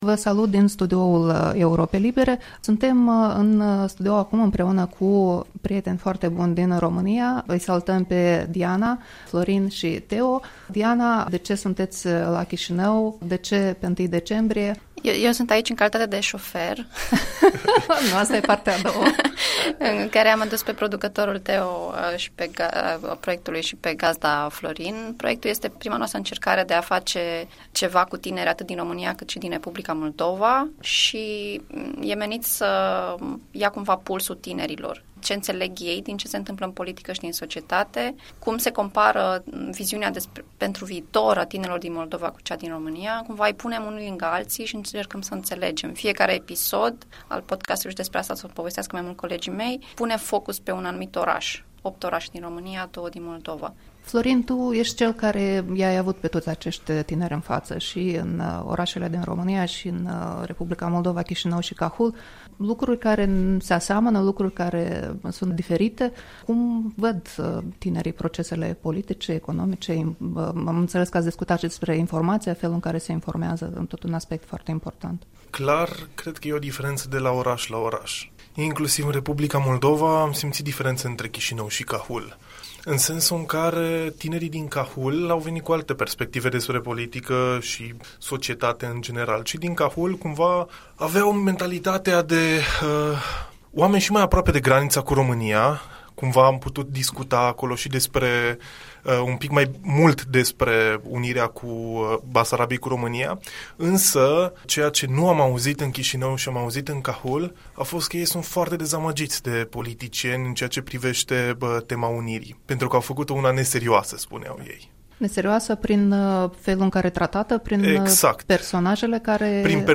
Interviul matinal cu producătorii seriei de podcasturi „Ceilalți”